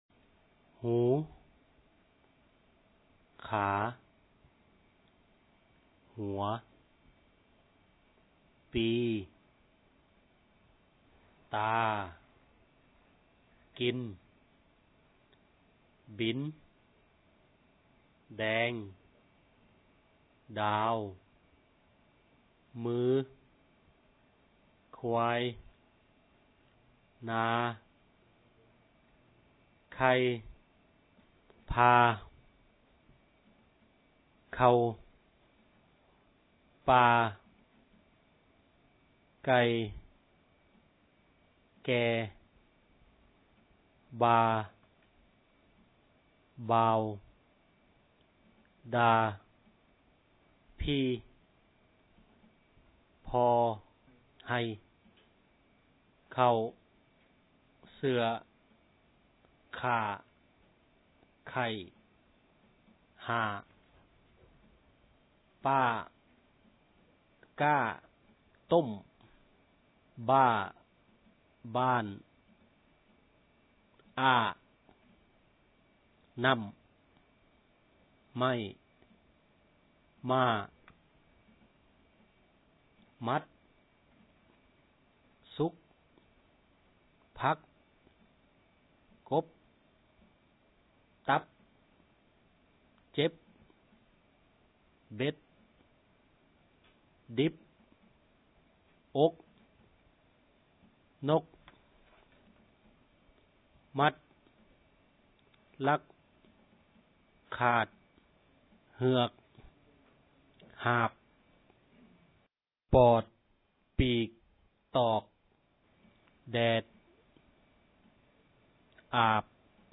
His dialect has 6 tones, similar to the dialects of southern Laos.
Click here or on the speaker icon to listen to the 6 tones of this reader.